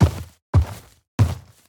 Minecraft Version Minecraft Version snapshot Latest Release | Latest Snapshot snapshot / assets / minecraft / sounds / mob / sniffer / step3.ogg Compare With Compare With Latest Release | Latest Snapshot
step3.ogg